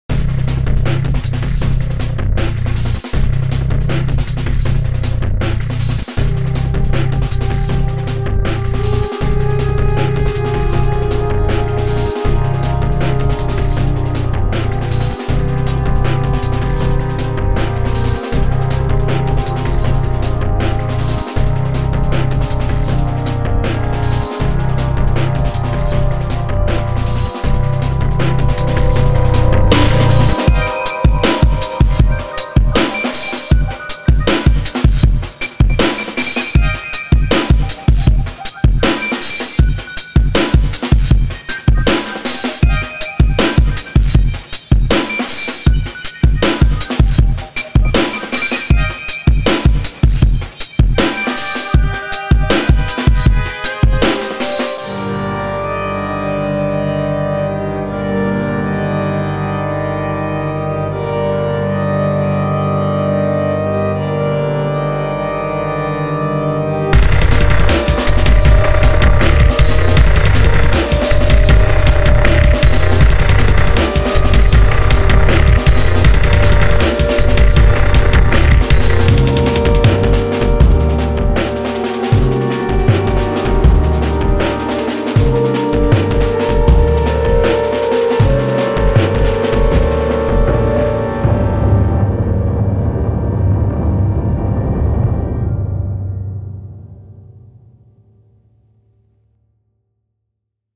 These "songs" are just the results of me playing with Renoise. I usually drop some sounds to the timeline and listen to them looped.